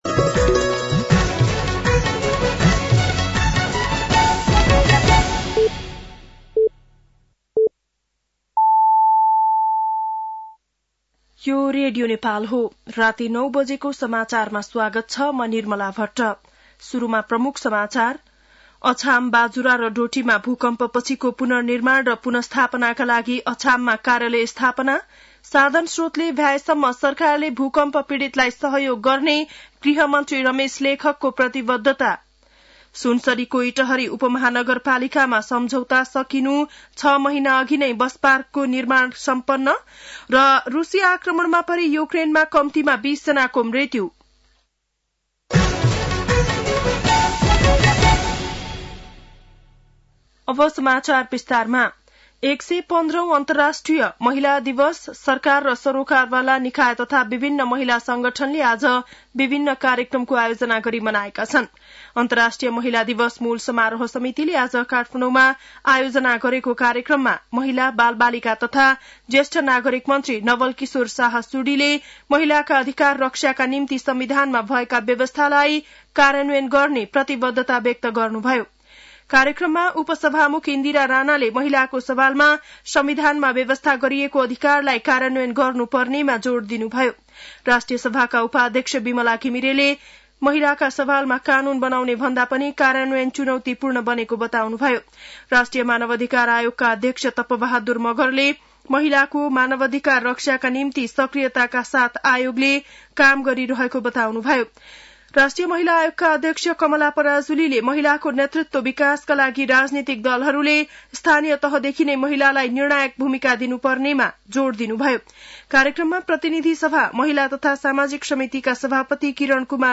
बेलुकी ९ बजेको नेपाली समाचार : २५ फागुन , २०८१
9-PM-Nepali-News-11-24.mp3